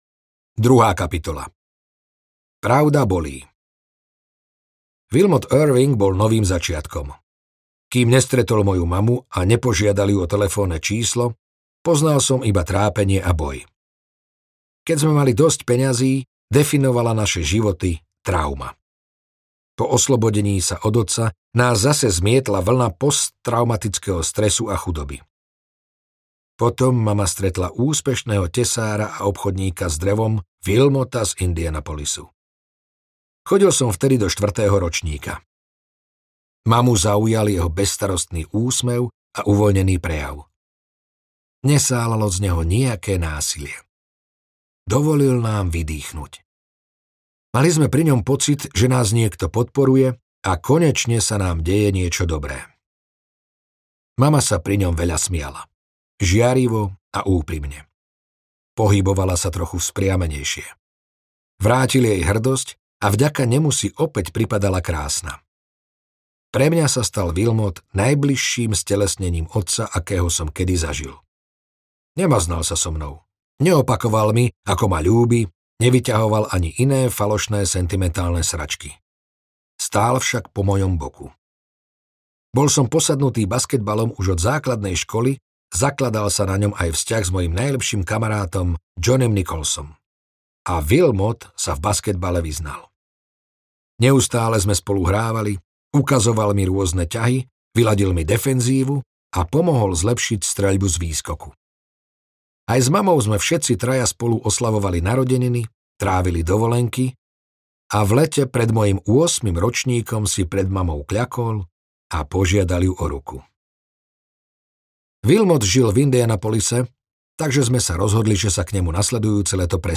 Audiokniha Nič ma nezlomí - David Goggins | ProgresGuru